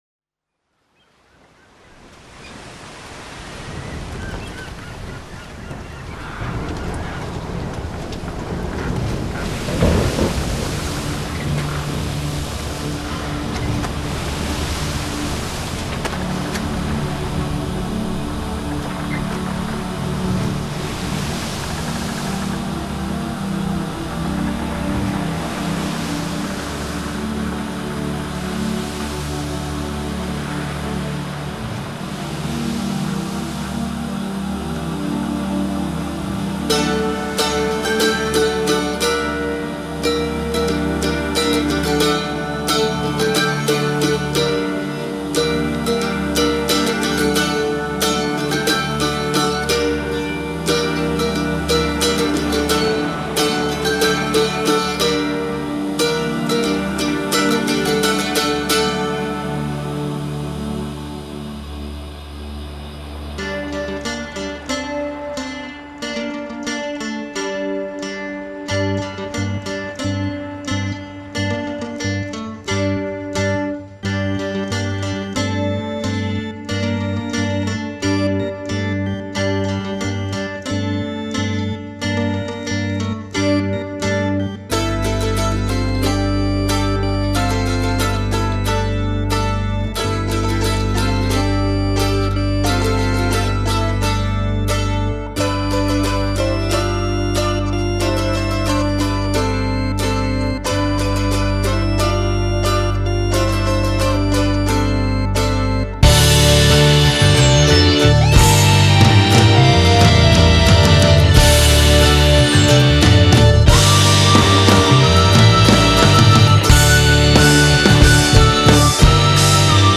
Retro Rock mit Mandoline und real Drums sucht feedback
Retro soll´s sein mit entsprechenden Instrumenten, textlich und dramaturgisch Albtraumverarbeitung mit geistigem Schiffsbruch ;-) Alles was Ihr hört bin ich.